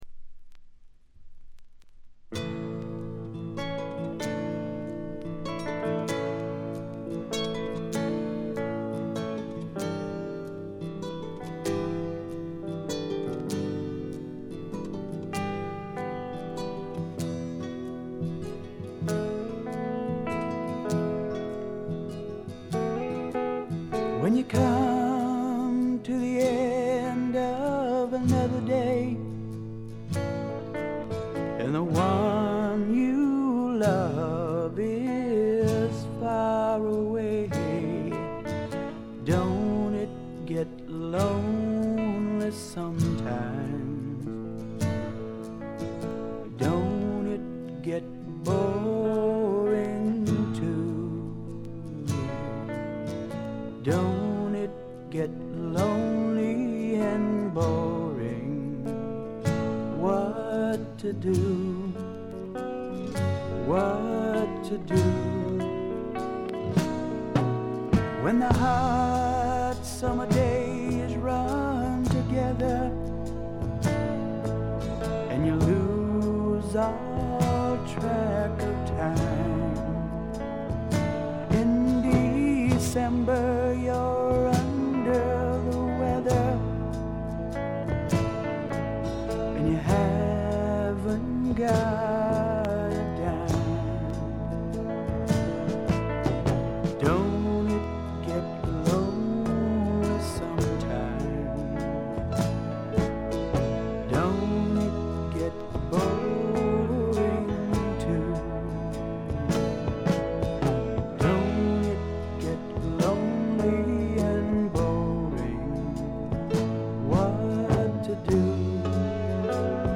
軽いチリプチが少々。
試聴曲は現品からの取り込み音源です。